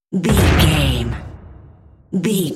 Cinematic drum hit trailer
Sound Effects
Atonal
heavy
intense
dark
aggressive